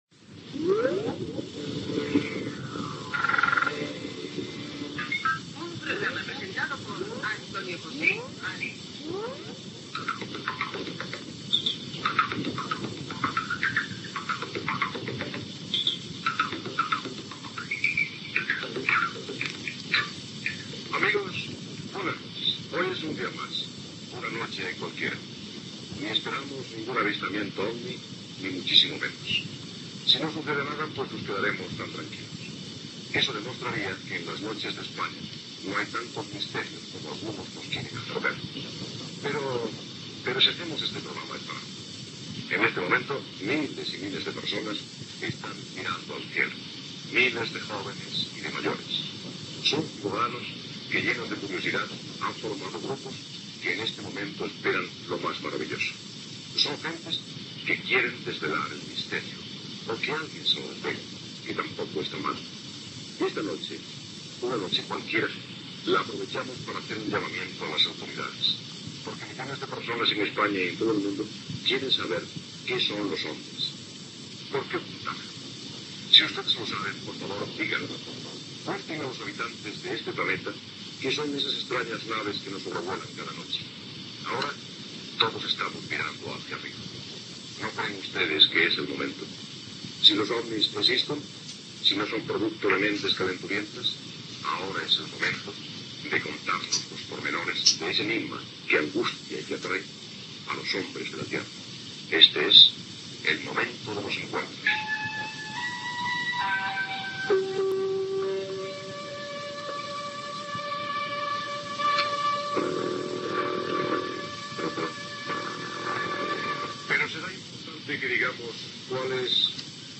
Careta del programa, presentació de la primera alerta OVNI
Divulgació